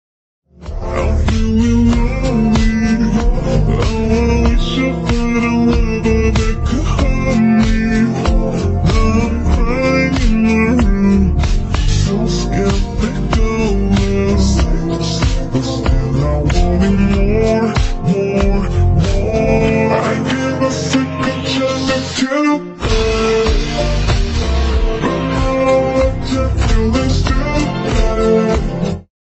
AI Horror